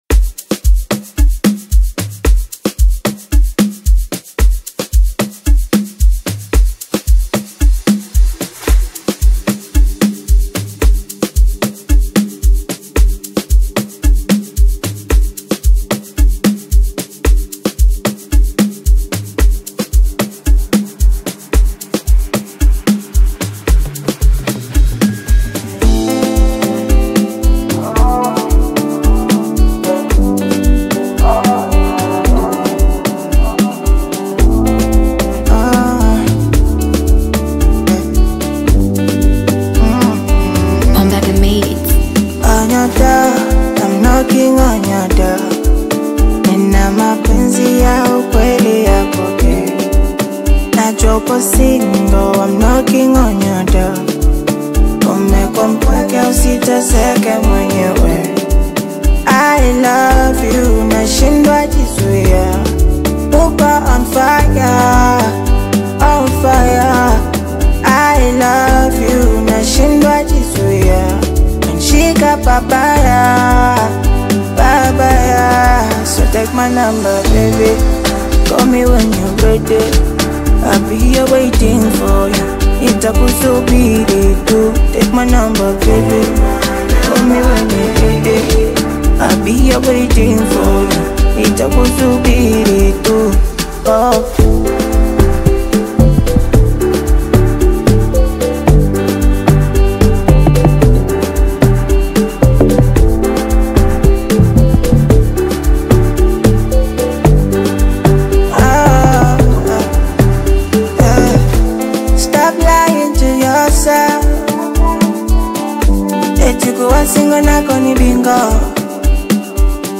heartfelt single
blending soulful vocals and emotive lyrics about love